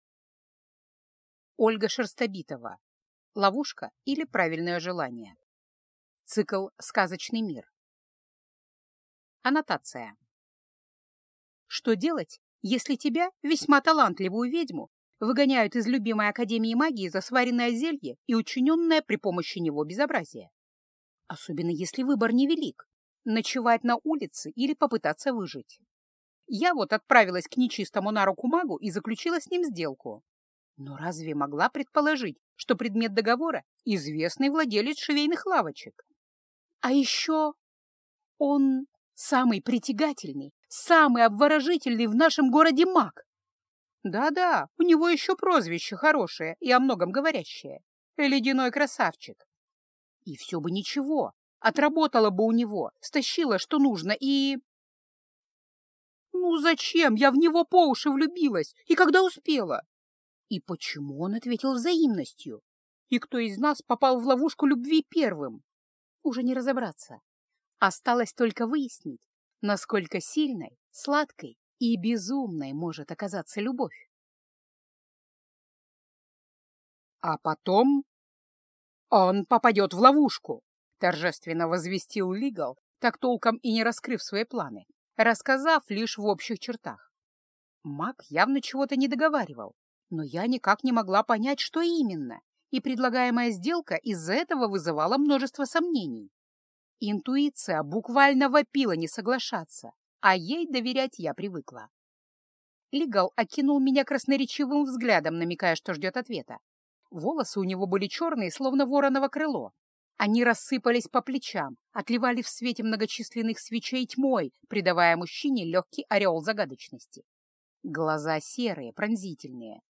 Аудиокнига Ловушка, или Правильное желание | Библиотека аудиокниг